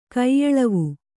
♪ kaiyaḷavu